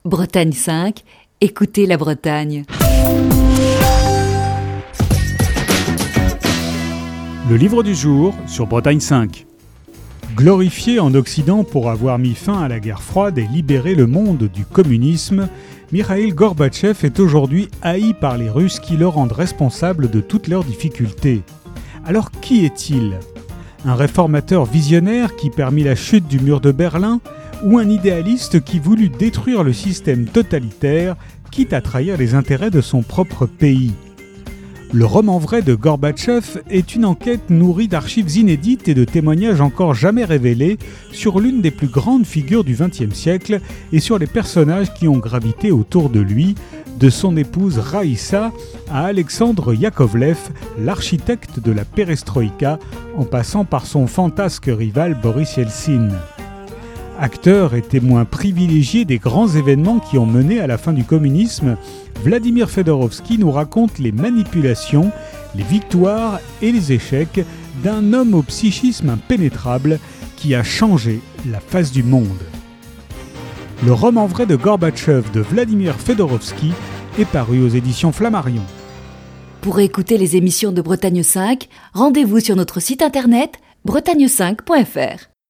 Chronique du 3 mars 2021.